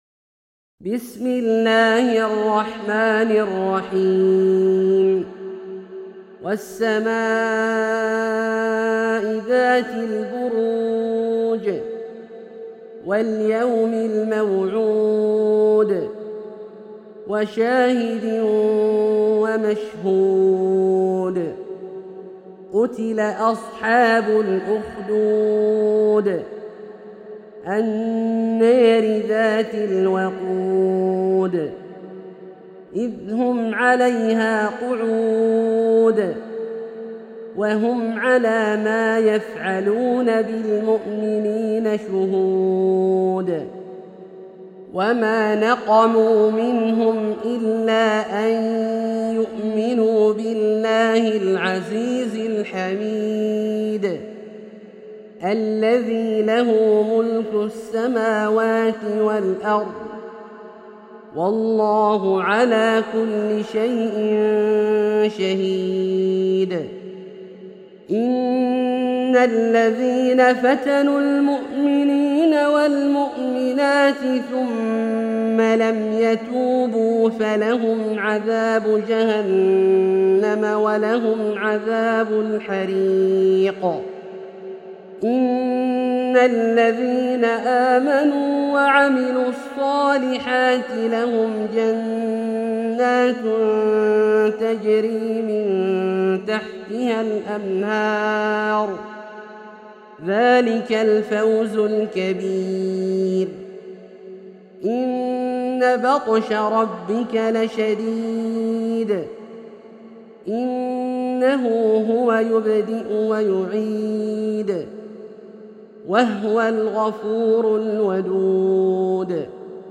سورة البروج - برواية الدوري عن أبي عمرو البصري > مصحف برواية الدوري عن أبي عمرو البصري > المصحف - تلاوات عبدالله الجهني